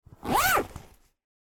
Download Free Bag Zipper Sound Effects
Bag Zipper